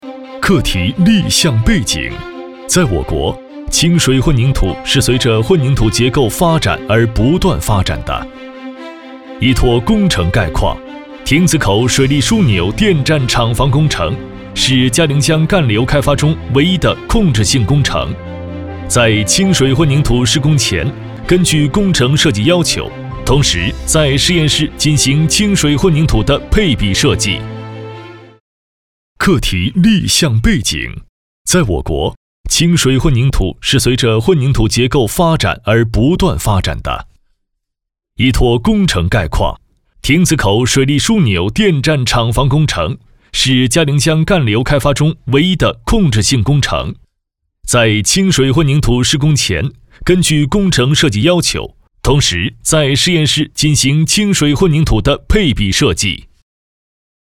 大气浑厚 企业专题
青年男播音，磁性稳重，大气时尚，走心独白。